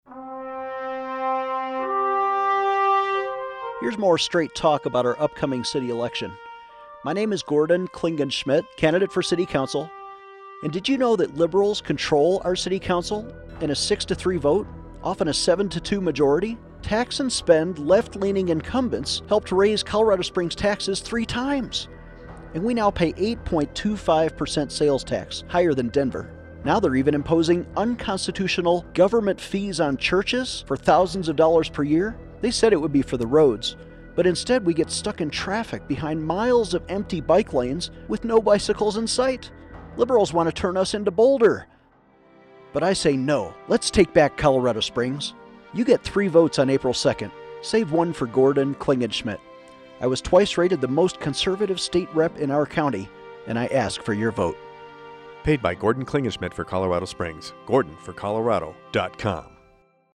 New radio ads on KVOR and KRDO.